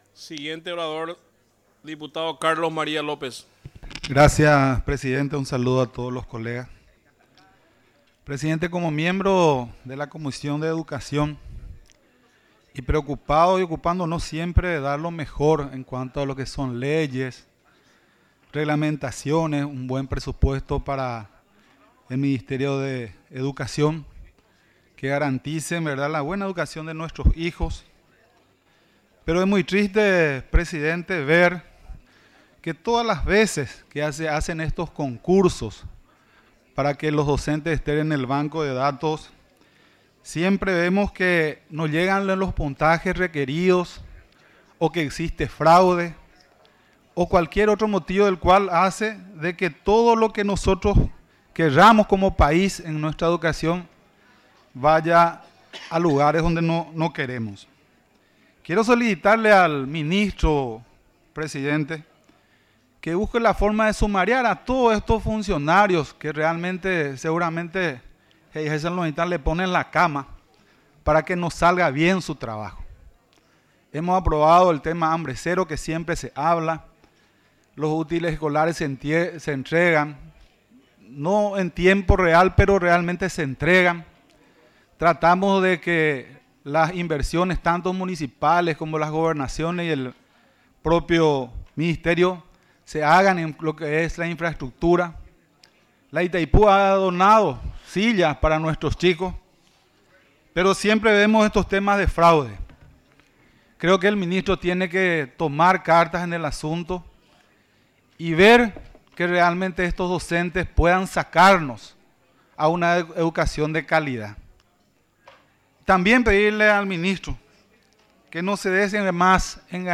Sesión Extraordinaria, 26 de agosto de 2025
Exposiciones verbales y escritas
14 – Dip Saul González